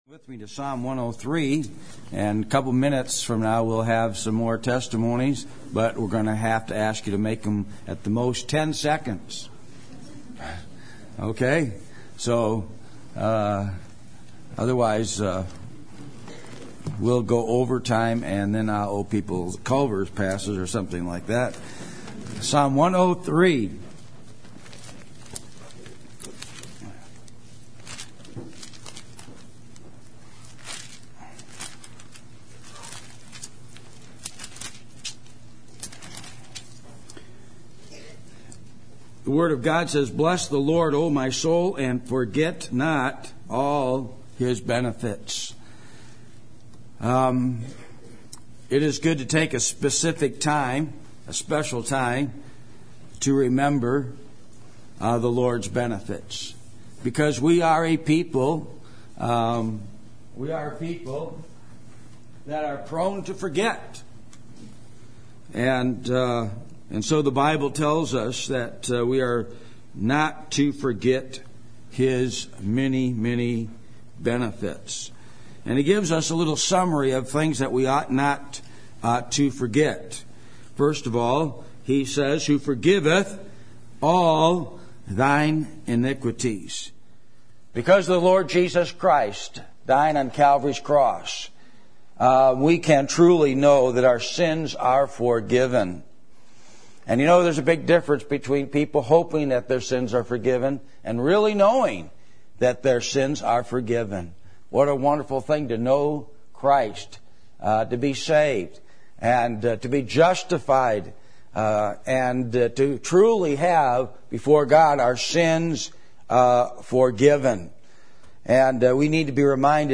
Thanksgiving Service, Msg: Forget Not All His Benefits
Service Type: Sunday Morning